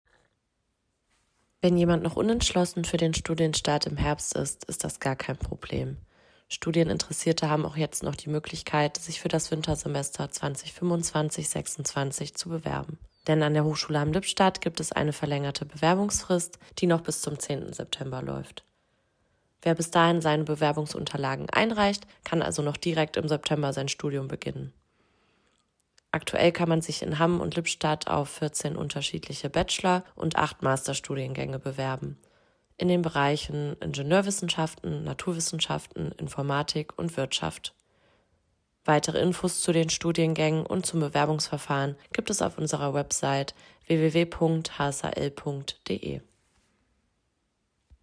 O-Ton Bewerbungsphase